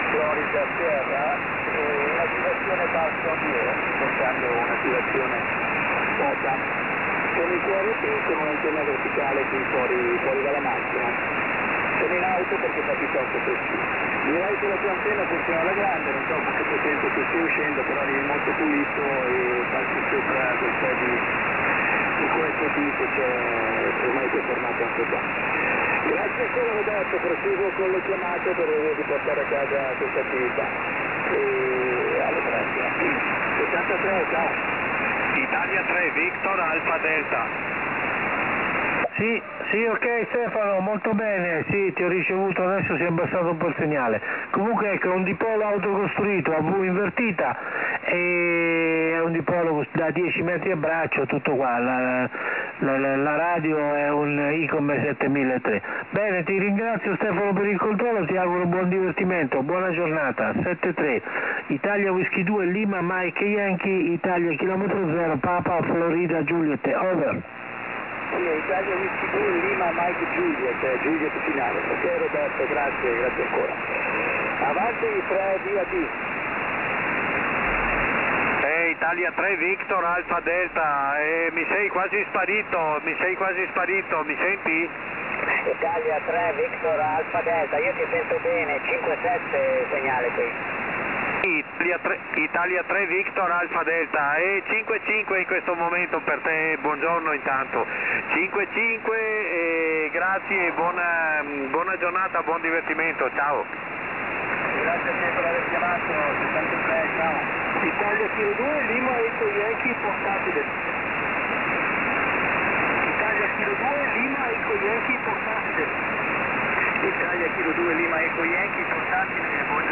Ne ho scovato uno ubicato in Sardegna e, strano a dirsi, piuttosto pulito e dotato di una discreta sensibilità.